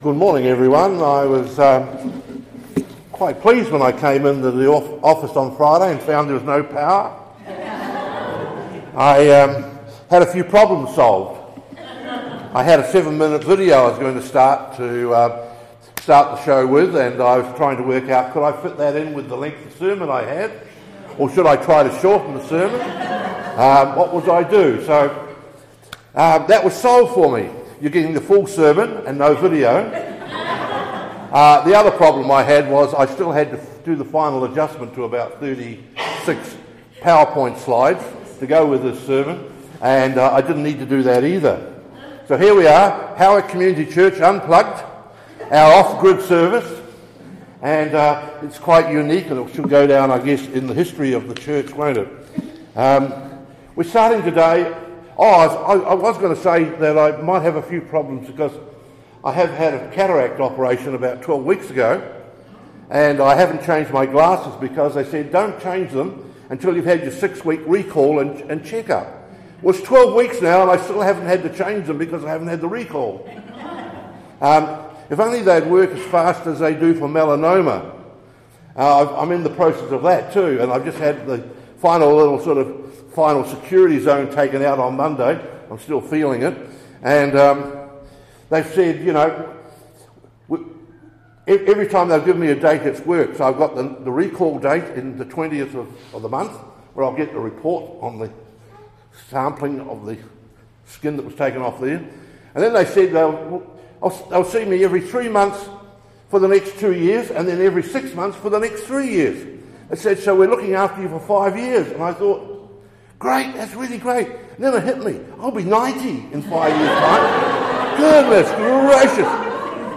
Sermons | HCC - Howick Community Church